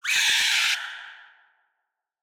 PixelPerfectionCE/assets/minecraft/sounds/mob/guardian/land_hit3.ogg at mc116
land_hit3.ogg